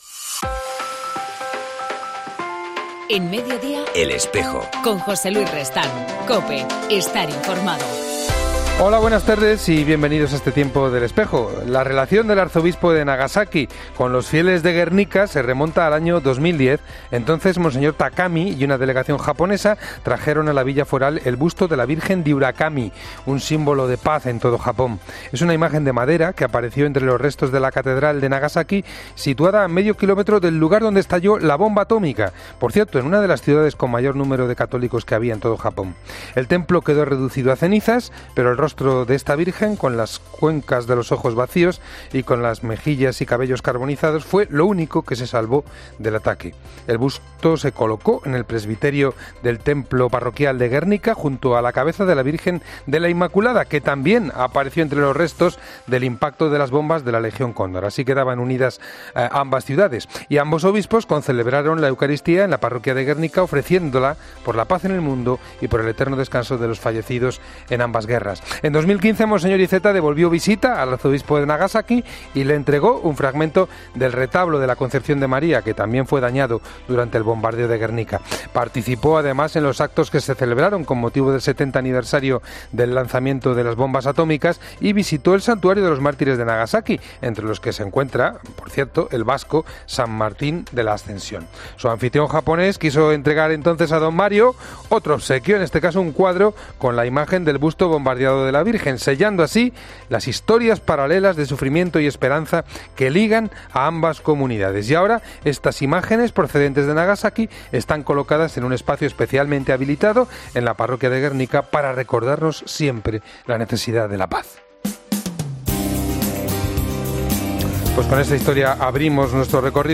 En El Espejo del 26 de abril entrevistamos